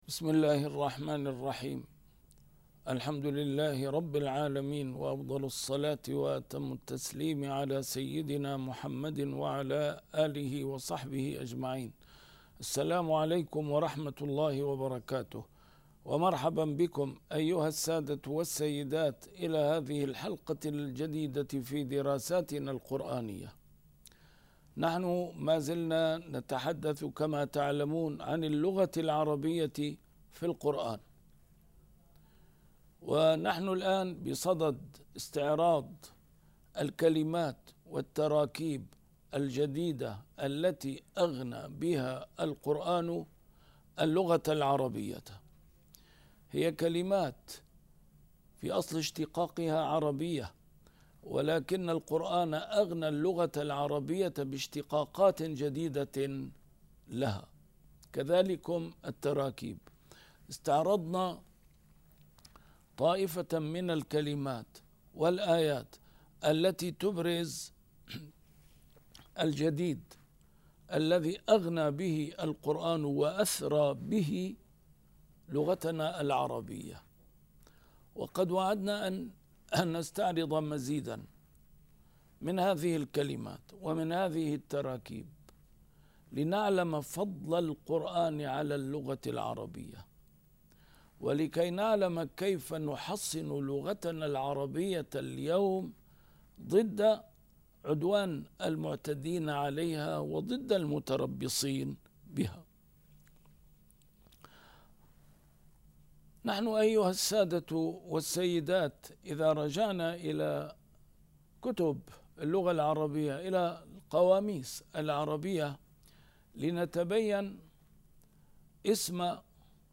A MARTYR SCHOLAR: IMAM MUHAMMAD SAEED RAMADAN AL-BOUTI - الدروس العلمية - اللغة العربية في القرآن الكريم - 4- كلمات أثرى القرآن الكريم اللغة العربية بها